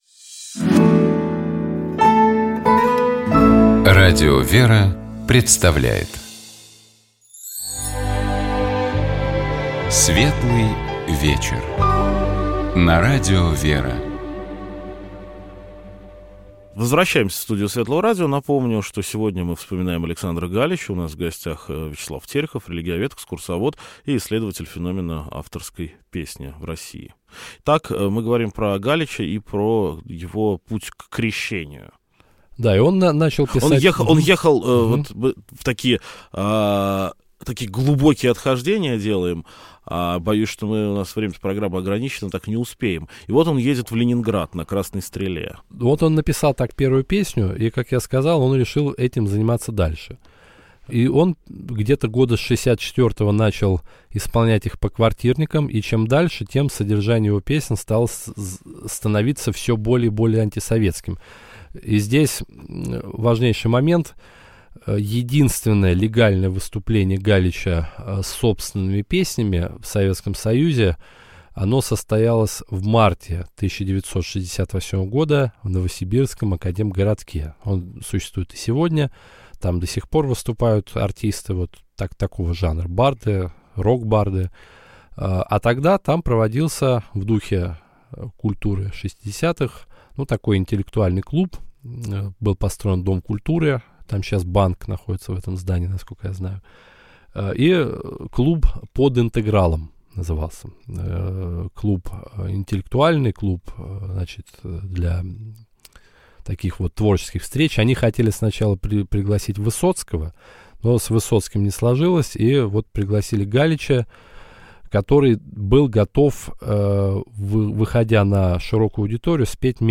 «Светлый вечер» на светлом радио.